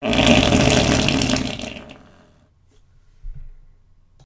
Fart Sound Effect [REED]
Farts Funny Lol sound effect free sound royalty free Memes